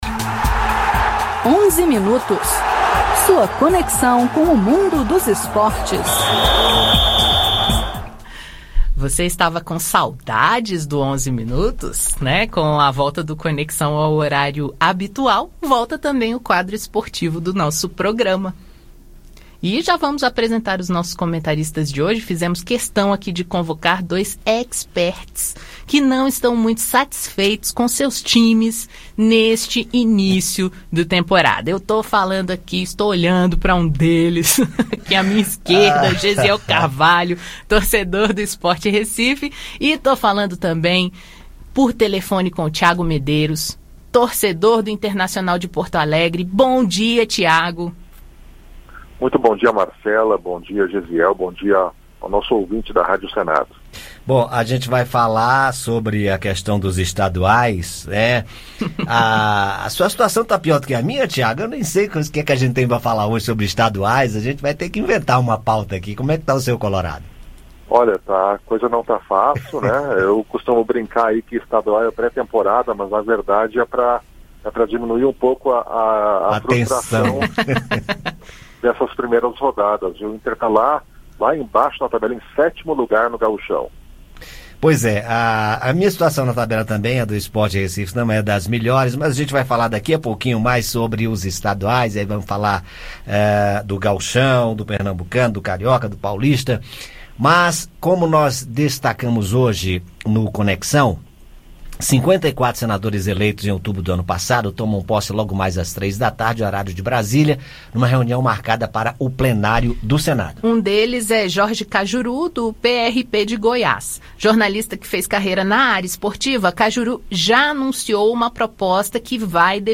Esse e outros assuntos você ouve no bate papo do “Onze Minutos”.